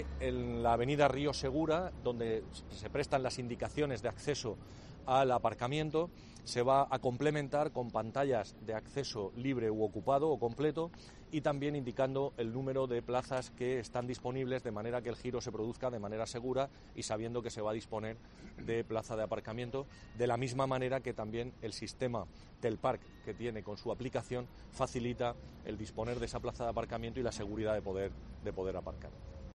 José Francisco Muñoz, concejal de Movilidad